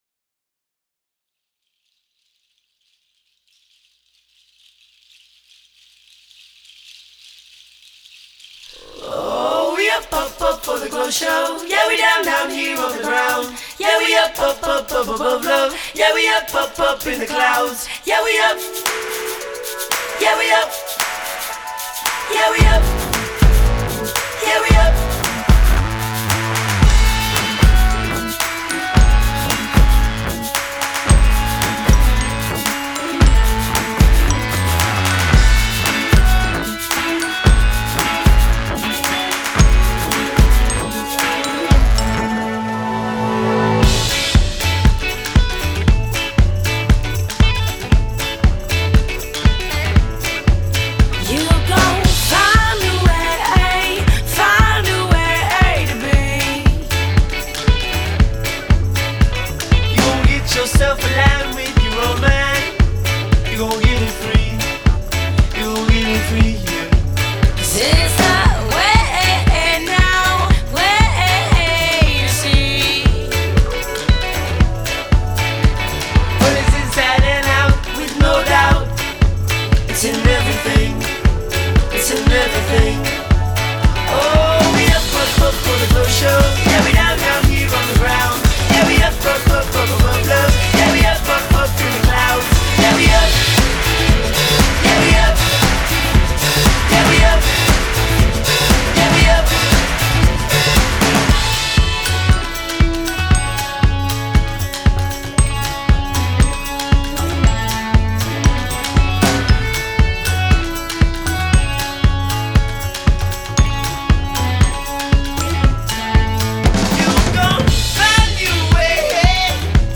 The quintessential summer jam
American indie pop group